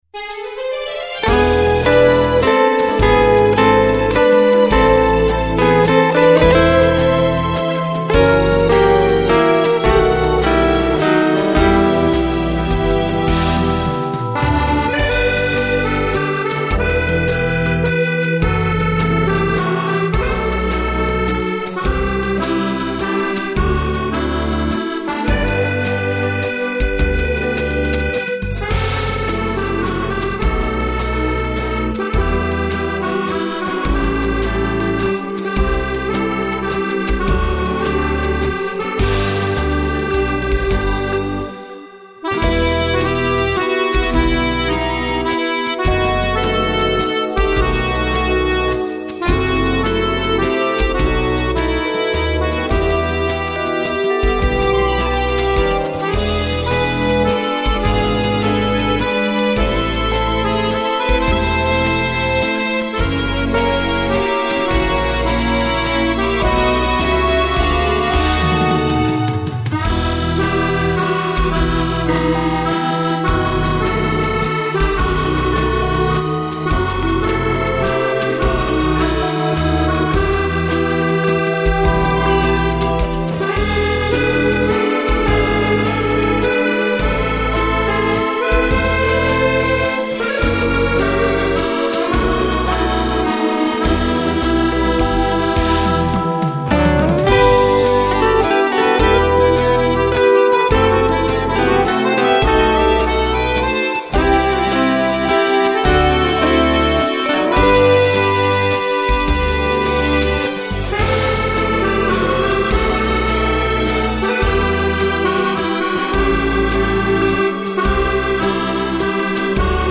version accordéon intégrale